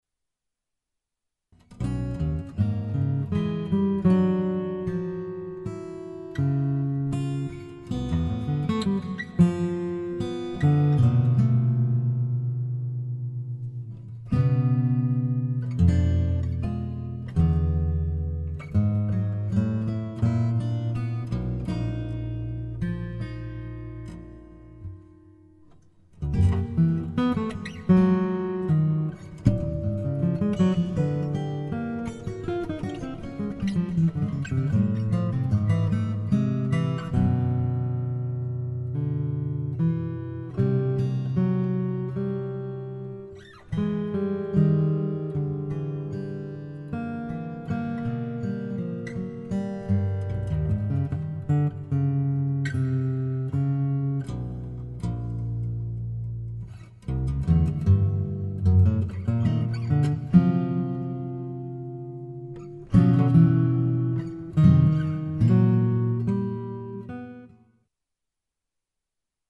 for 6-string and extended baritone guitar duet